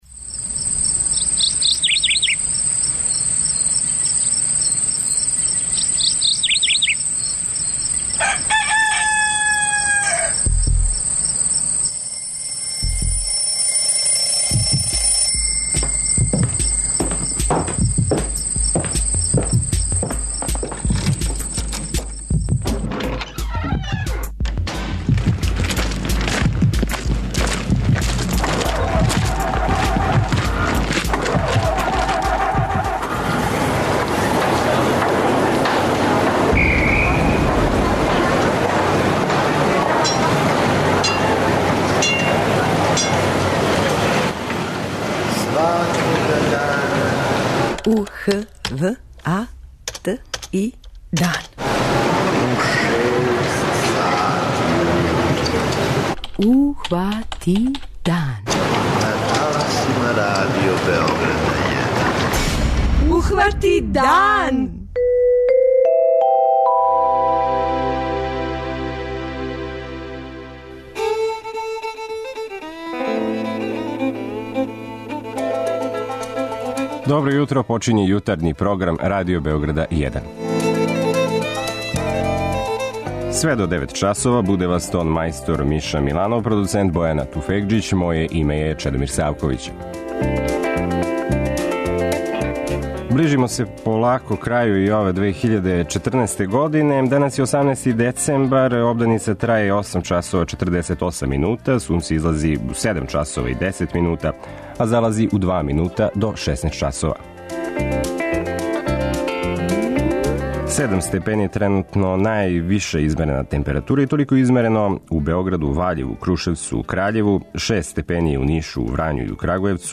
Наш репортер јавља се са моста "Михајло Пупин", који повезује Земун и Борчу.